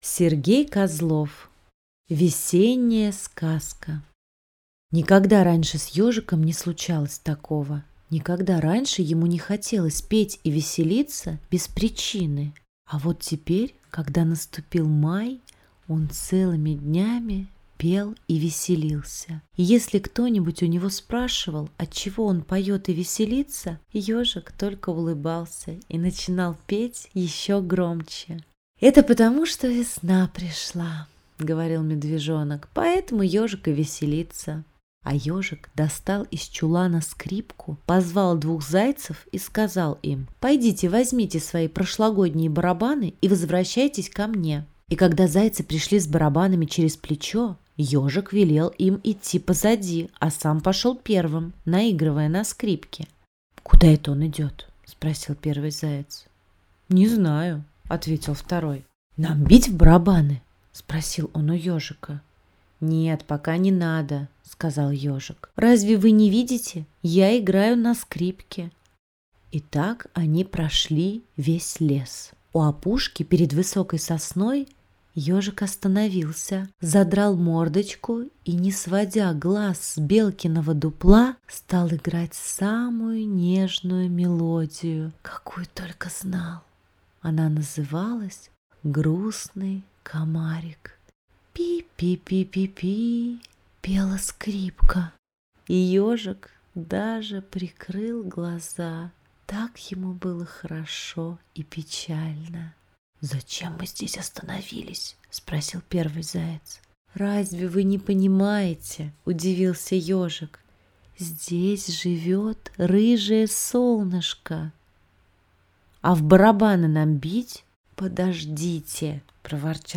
Весенняя сказка - аудиосказка Сергея Козлова - слушать онлайн | Мишкины книжки